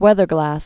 (wĕthər-glăs)